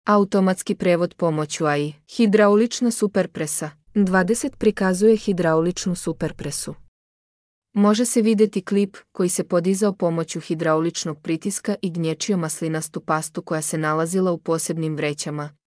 Аудио водич / Audio vodič.